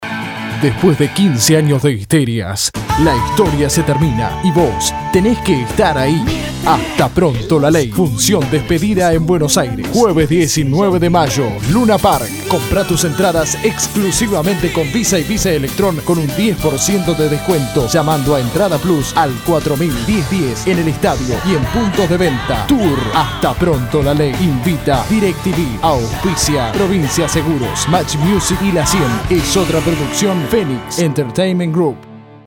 Masculino
Espanhol - Argentina
Possuo estúdio próprio com alta qualidade, facilitando o processo de produção.
Conversational. Sincere. Real. Natural. Warm and deep in resonance
Middle Aged